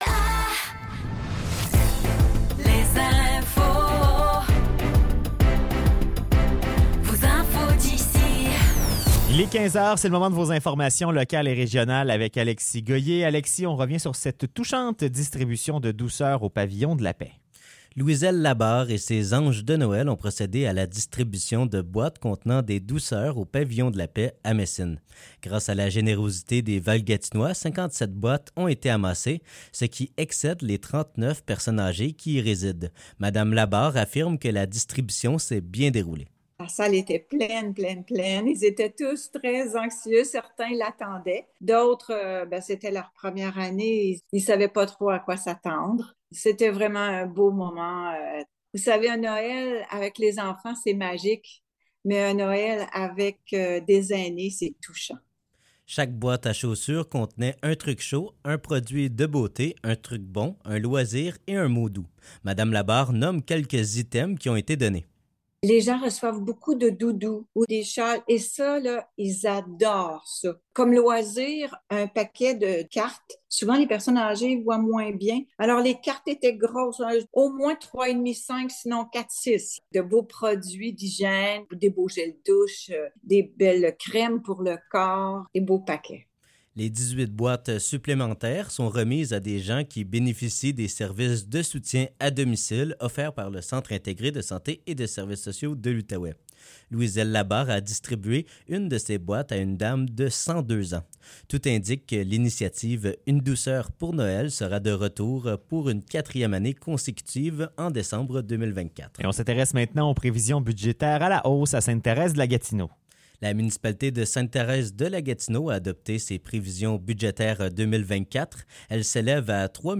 Nouvelles locales - 22 décembre 2023 - 15 h